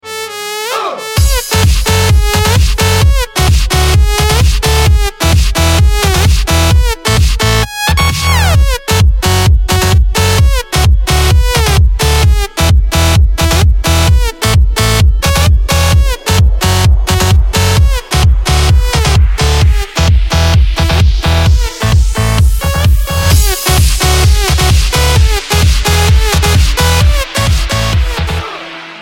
Poszukuje Piosenki Posiadam Fragment Nagrania z 2011 Roku - Muzyka elektroniczna